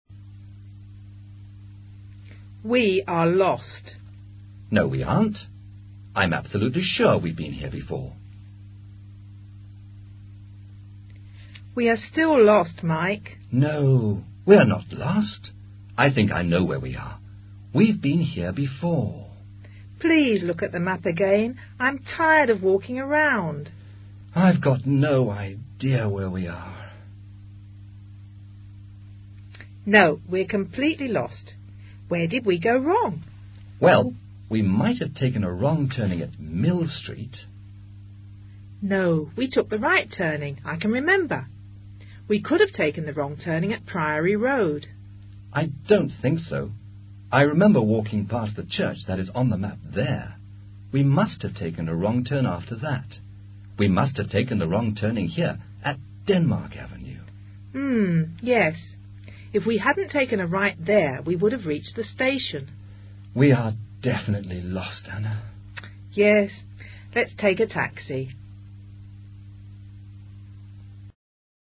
Diálogo entre dos amigos que se pierden recorriendo una ciudad.